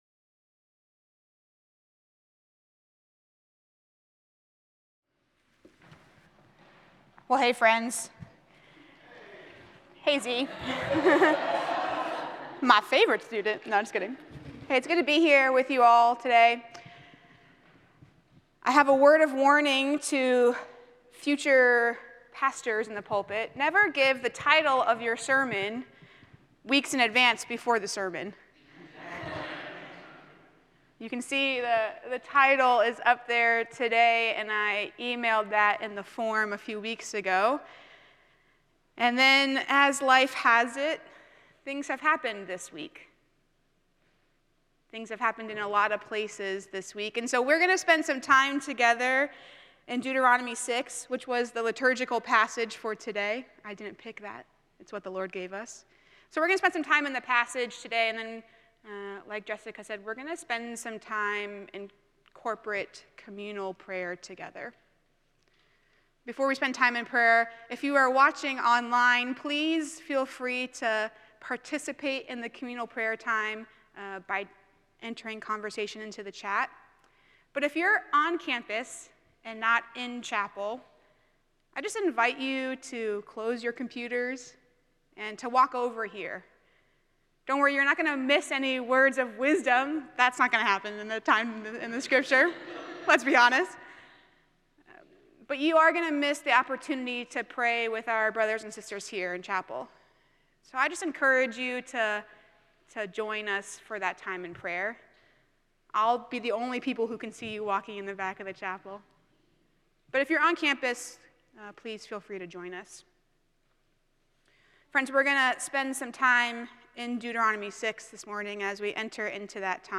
The following service took place on Wednesday, October 11, 2023.
Sermon